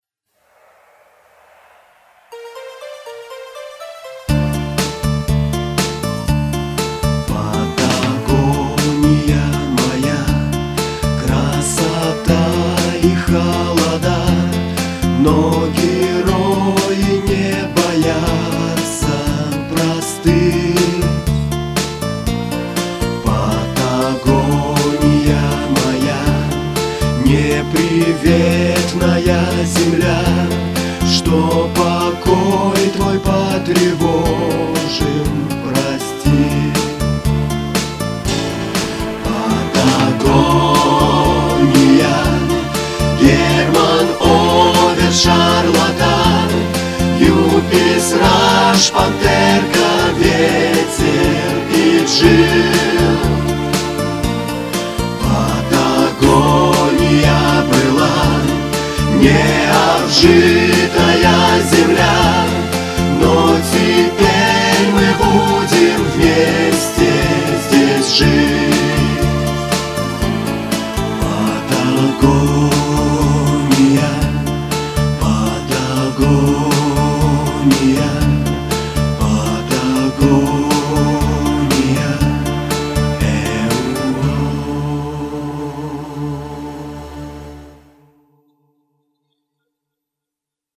(патриотическая песня)